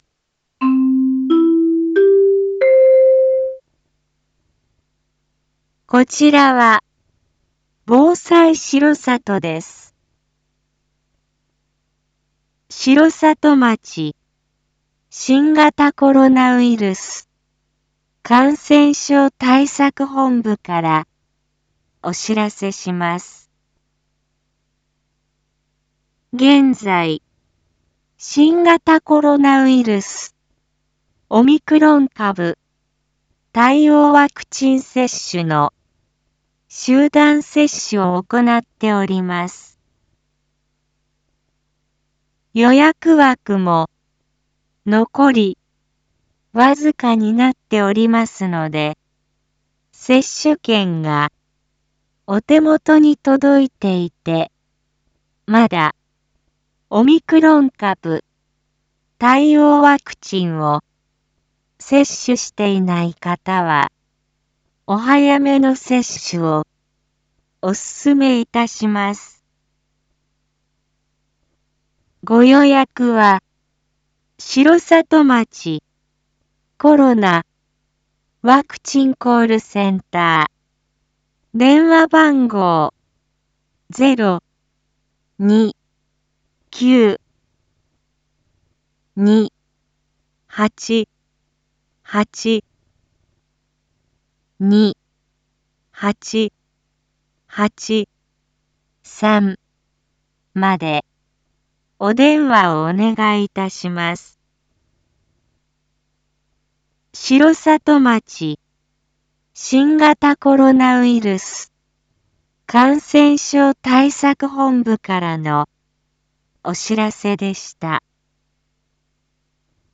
Back Home 一般放送情報 音声放送 再生 一般放送情報 登録日時：2023-01-31 19:02:10 タイトル：新型コロナウイルスワクチン接種予約について インフォメーション：こちらは、防災しろさとです。